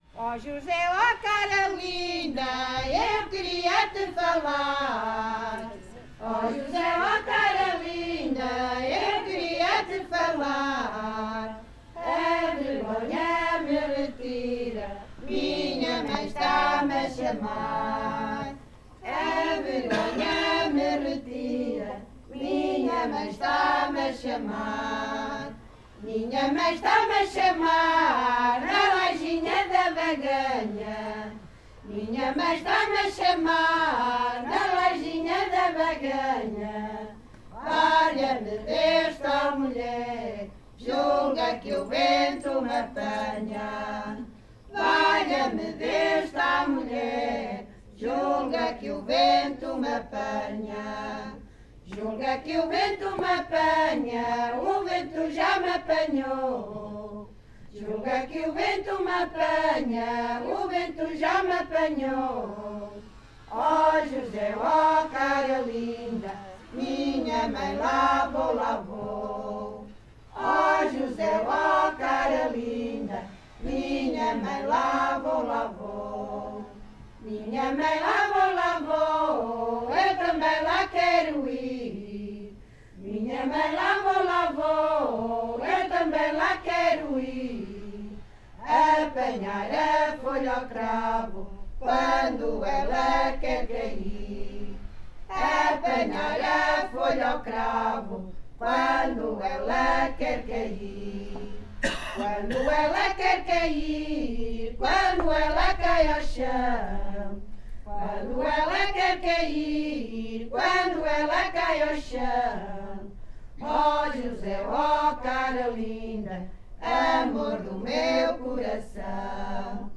Grupo Etnográfico de Trajes e Cantares do Linho de Várzea de Calde durante o encontro SoCCos em Portugal - O ripar do linho (Versão 2).
NODAR.00682 – Grupo Etnográfico de Trajes e Cantares do Linho durante o encontro SoCCos em Portugal – O ripar do linho (Versão 2) (Várzea de Calde, Viseu)